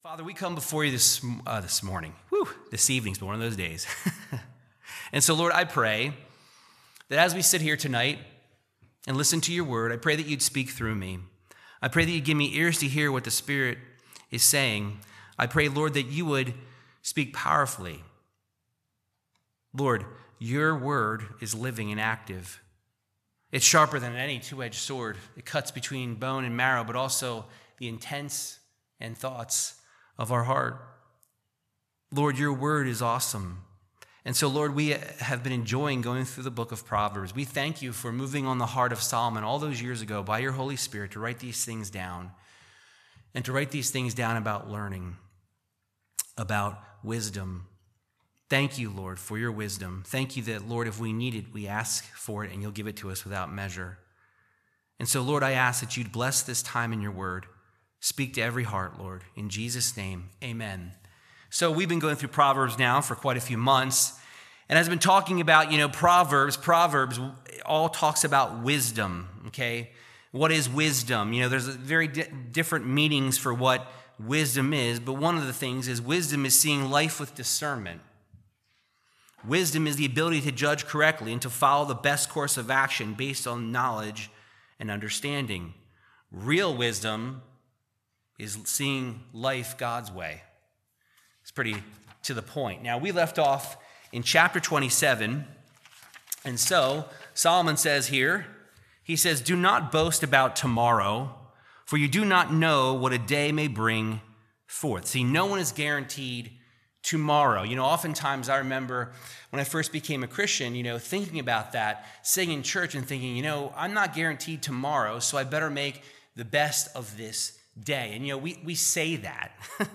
Verse by verse Bible teaching through the wisdom of King Solomon in Proverbs 27-28:13